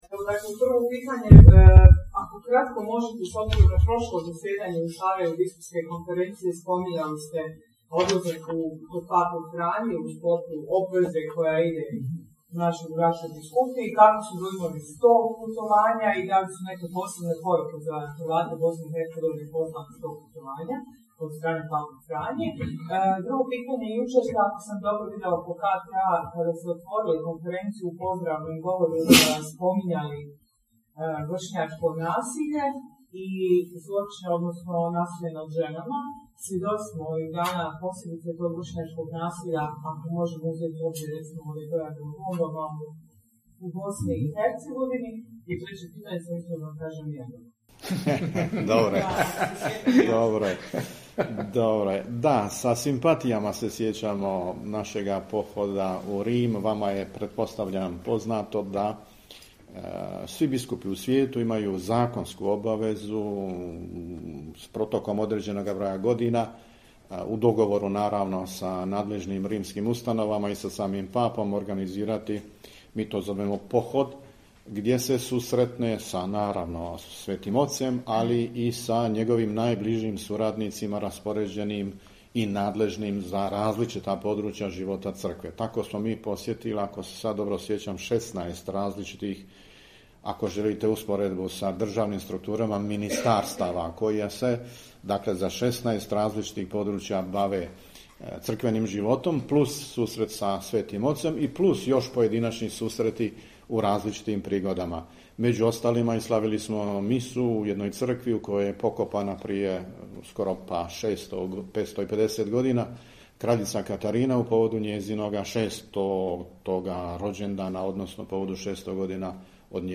Video: Nadbiskup Vukšić i biskup Palić govorili na tiskovnoj konferenciji o 91. redovitom zasjedanju Biskupske konferencije BiH